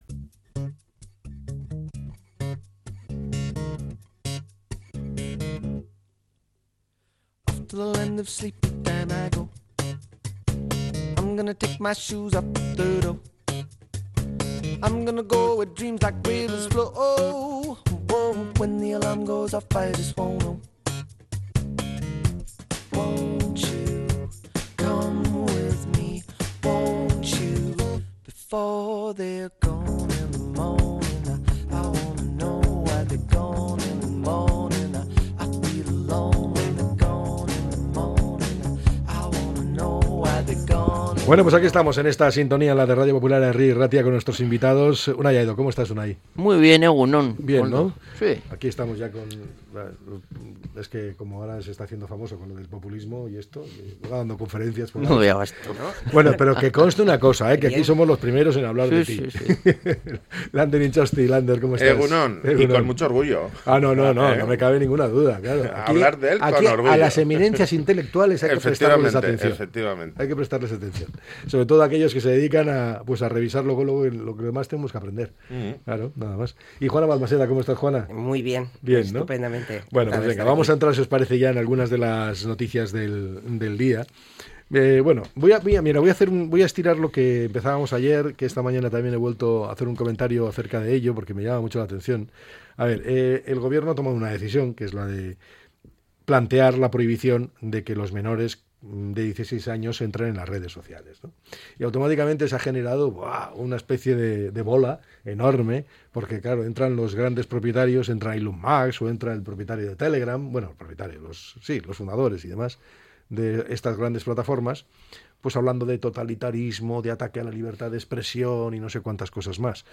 La Tertulia 05-02-26.